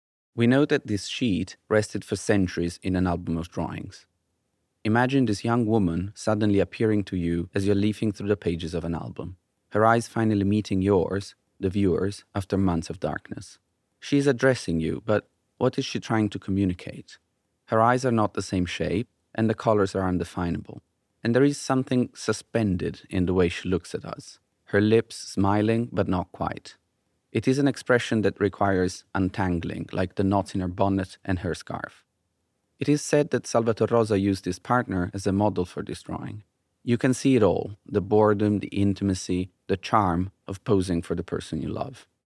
In addition, nine objects include a personal reflection by one of the curators.
Curator's Personal Reflection